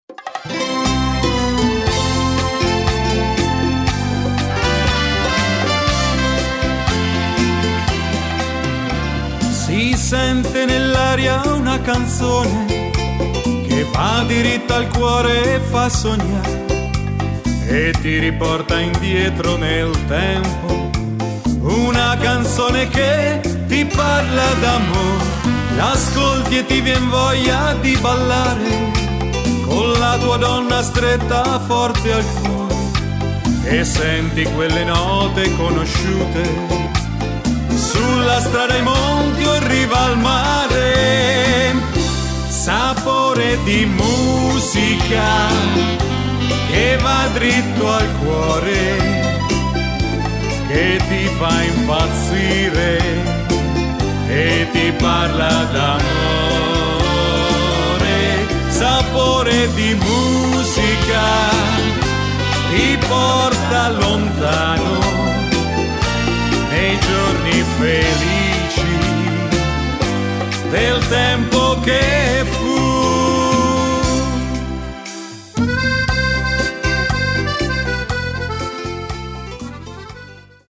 Beguine bachata
Uomo